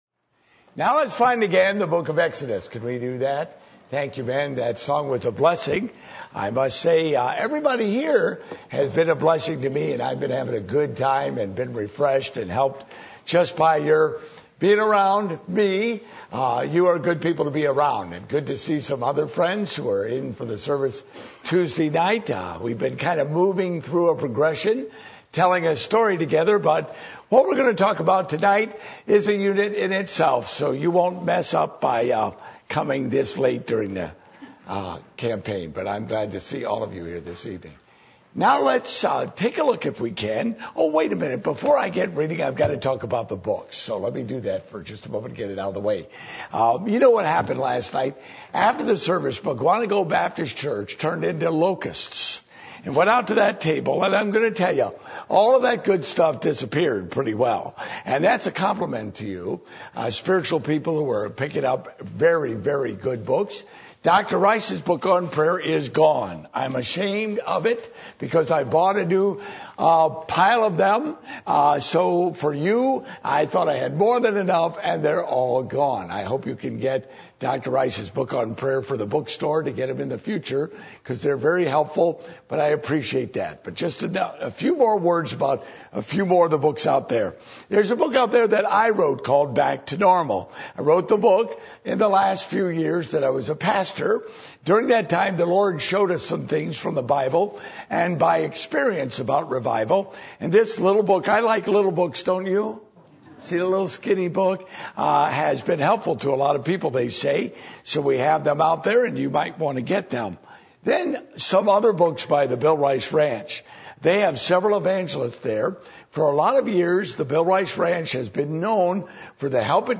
Service Type: Revival Meetings